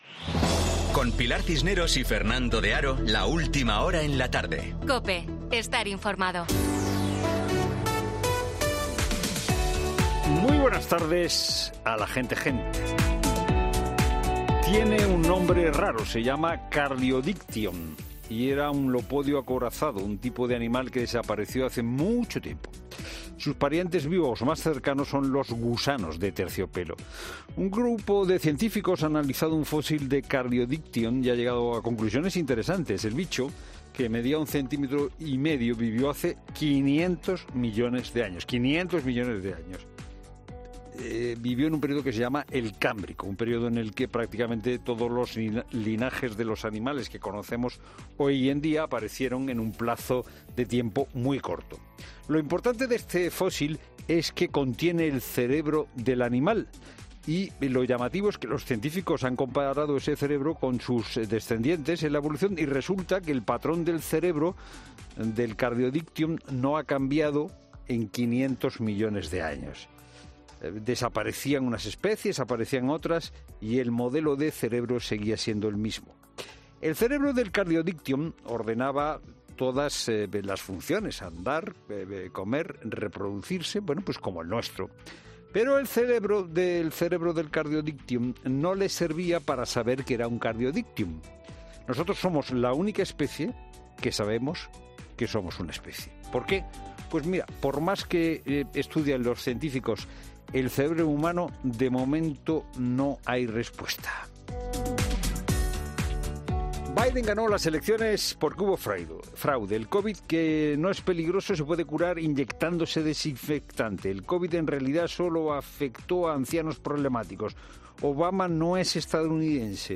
Monólogo de Fernando de Haro
El copresentador de 'La Tarde', Fernando de Haro, analiza la actualidad de este miércoles